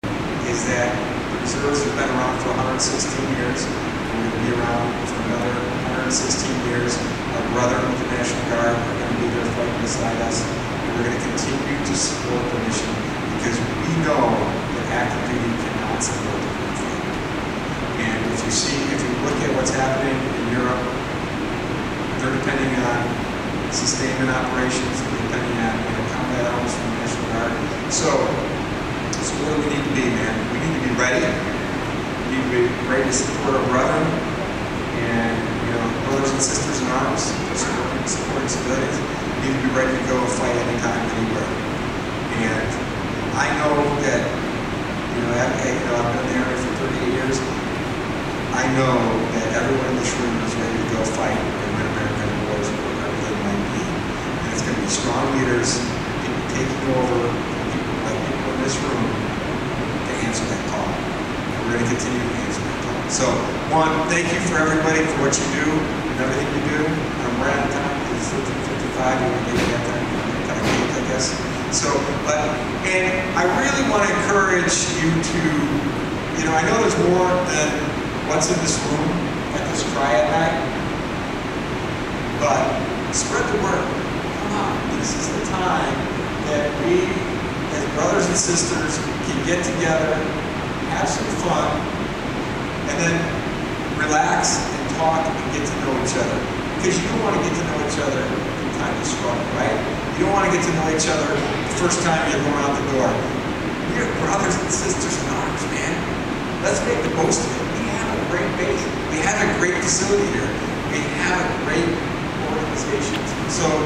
Fort McCoy Senior Commander Maj. Gen. Matthew Baker, commanding general of the 88th Readiness Division, speaks to hundreds of Fort McCoy community members April 23, 2024, at McCoy's Community Center during the Army Reserve's 116th Birthday Celebration at Fort McCoy, Wis. Baker discussed leadership and the history of the Army Reserve and more.